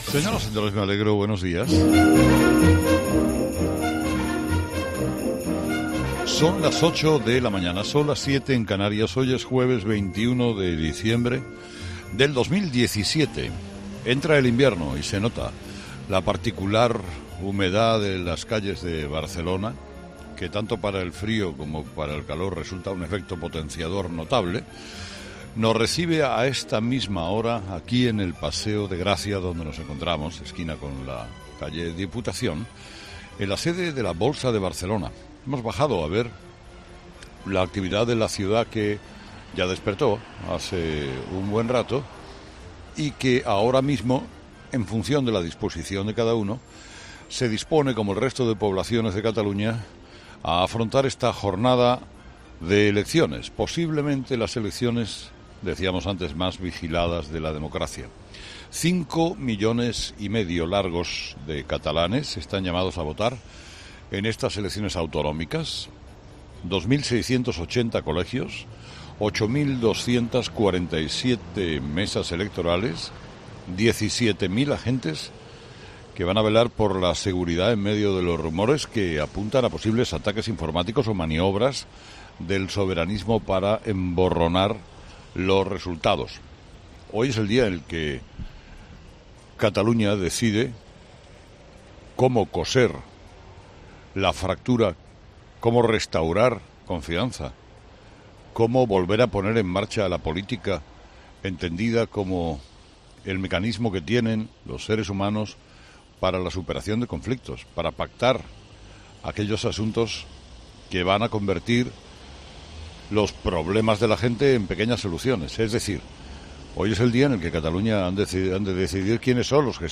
Herrera, en el Paseo de Gracia